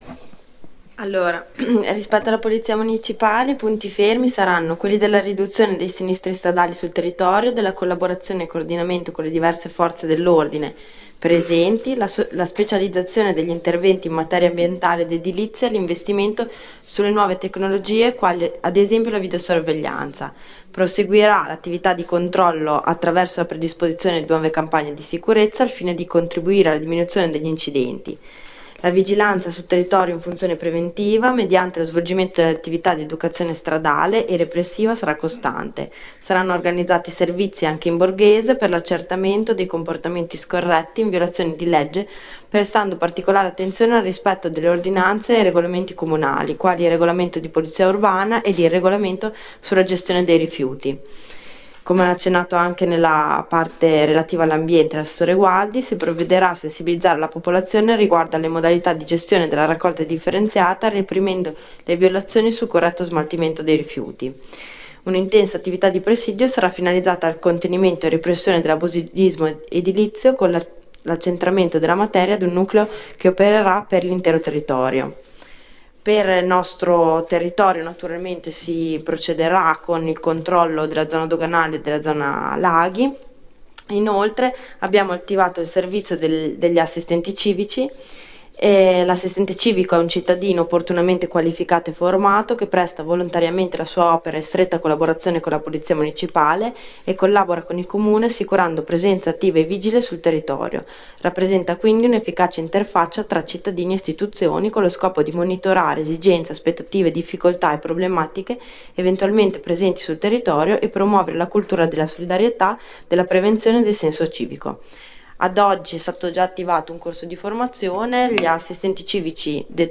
Accedendo a questa pagina è possibile ascoltare la registrazione della seduta del Consiglio comunale.